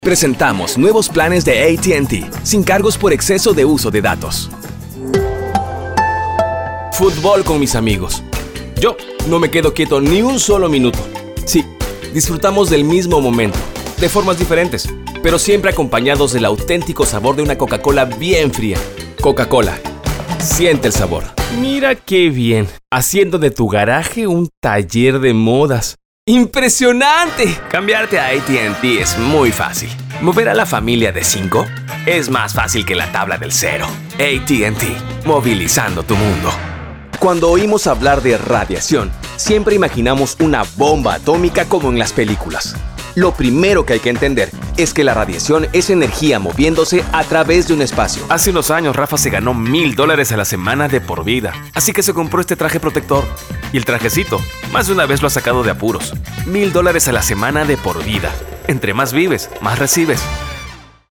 Commercial VO and Character.
Languages: Spanish (Latin American) Accent: Mexican, South American (Argentinian), South American (Peruvian) Ages: Middle Aged, Senior, Teen, Young Adult Special Skills: Animation, Audiobooks, Business, Documentaries, Educational, Internet Video, Movie Trailers, Podcasting, Radio, Telephone, Television, Videogames
Sprechprobe: Werbung (Muttersprache):
NATURAL JOVEN 3.mp3